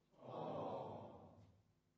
描述：声音是在比利时根特的大规模人民录音处录制的。
一切都是由4个麦克风录制的，并直接混合成立体声进行录音。在这里，人们说的是字母表的一部分，每个字母都有一种情感。这是字母o，我很伤心。
声道立体声